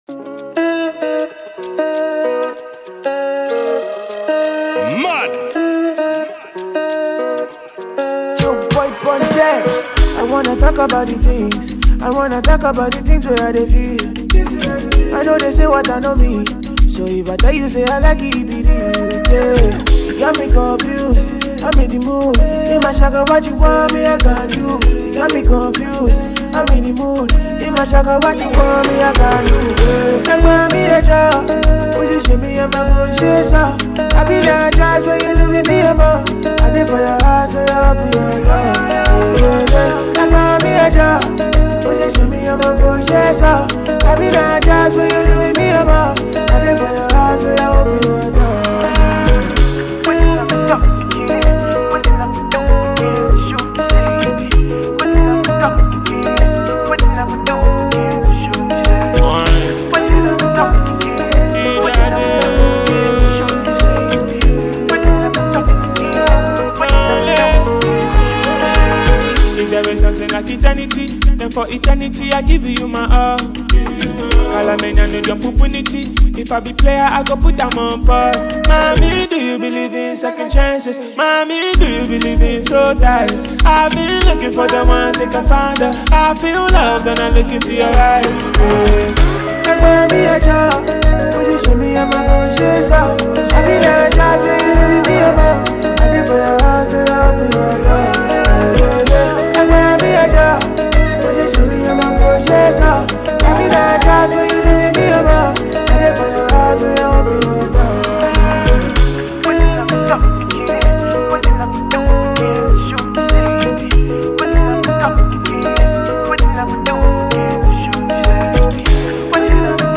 Ghanaian rapper and singer